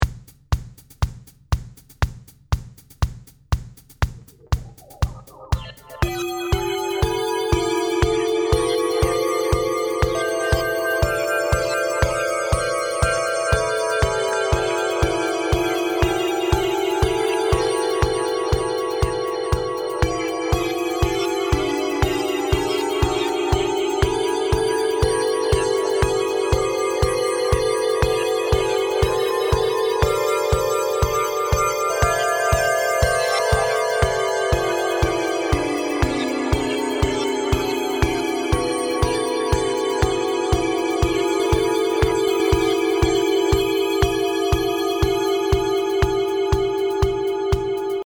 適当に入れただけなので変な音楽ができました。